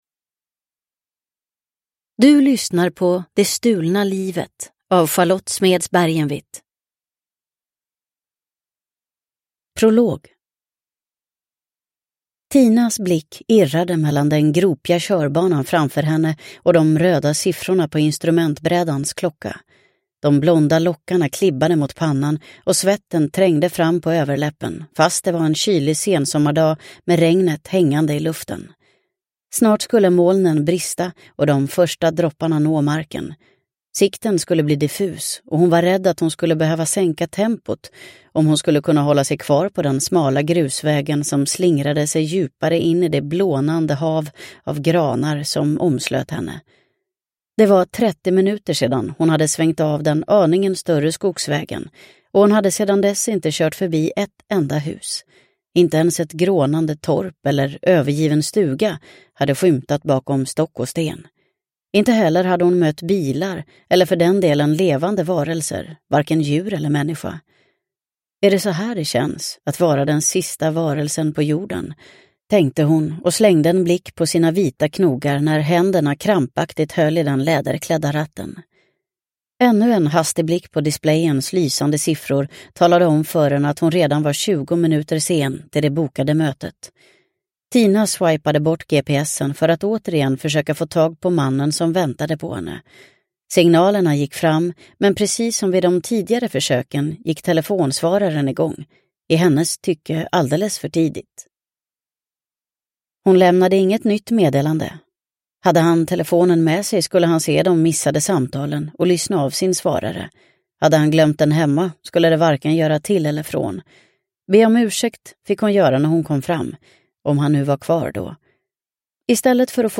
Det stulna livet – Ljudbok – Laddas ner